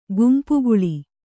♪ gumpuguḷi